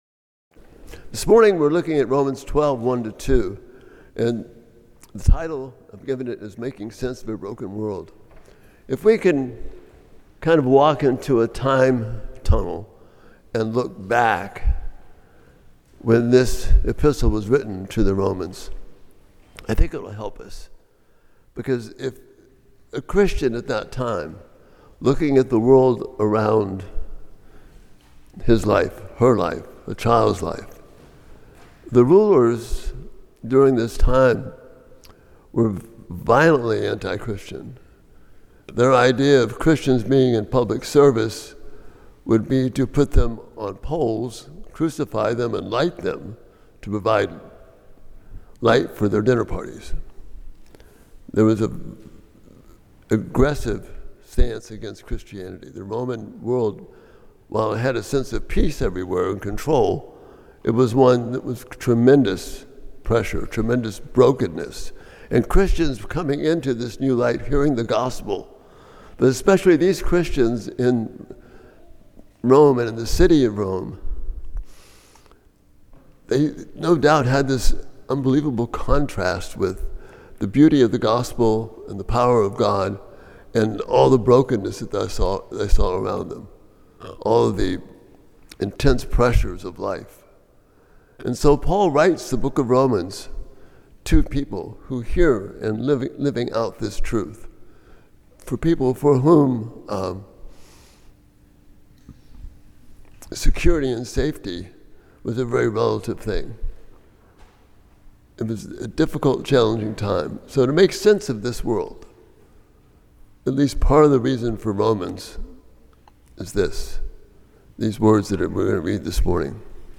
Romans 12:1-2 Service Type: Sunday Morning Romans 12:1-2 « Peace!